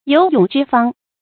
有勇知方 注音： ㄧㄡˇ ㄩㄥˇ ㄓㄧ ㄈㄤ 讀音讀法： 意思解釋： 有勇氣且知道義。